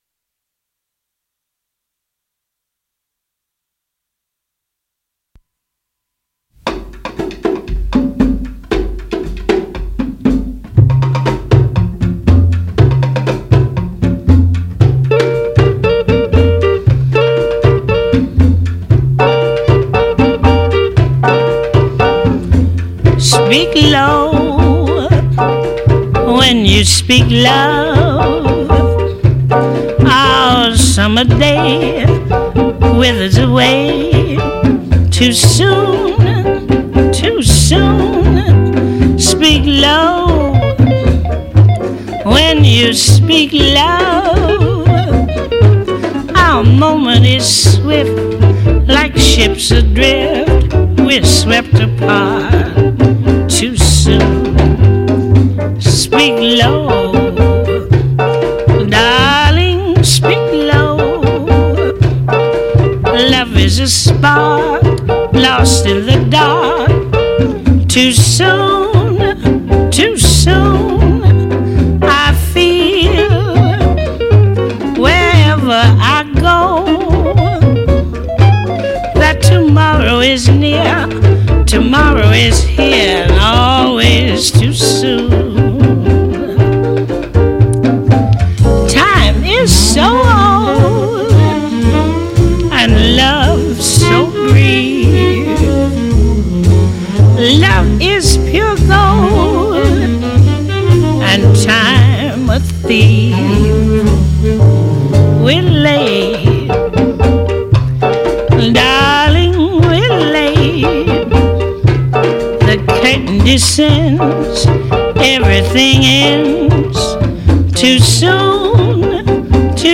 Intervista telefonica